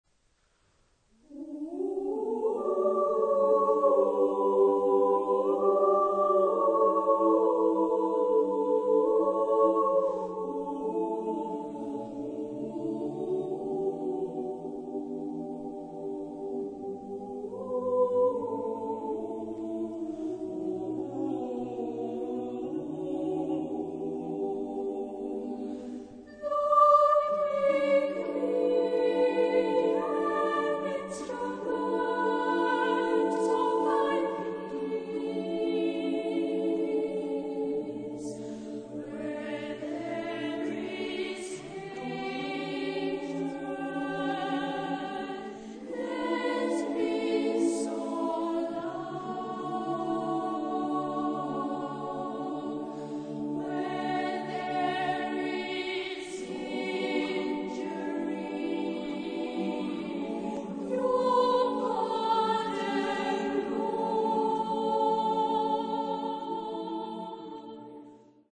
Epoque: 20th century
Genre-Style-Form: Sacred
Type of Choir: SATB  (4 mixed voices )
Instrumentation: Keyboard